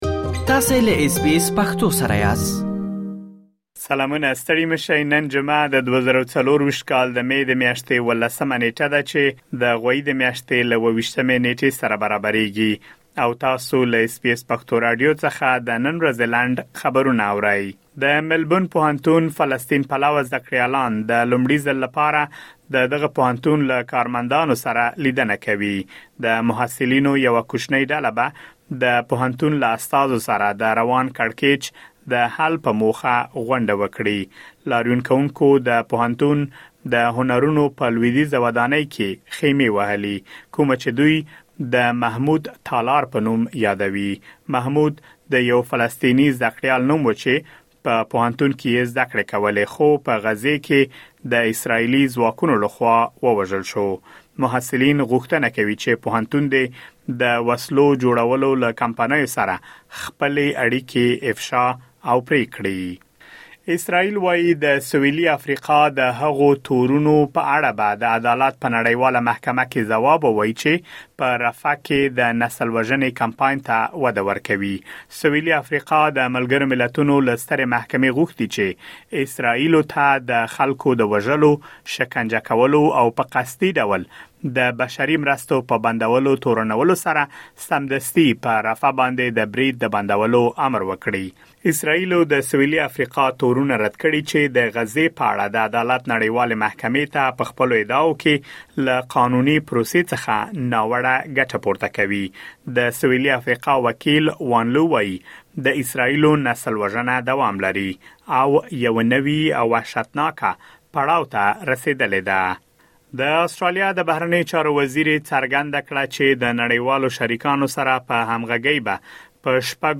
د اس بي اس پښتو د نن ورځې لنډ خبرونه|۱۷ مې ۲۰۲۴
د اس بي اس پښتو د نن ورځې لنډ خبرونه دلته واورئ.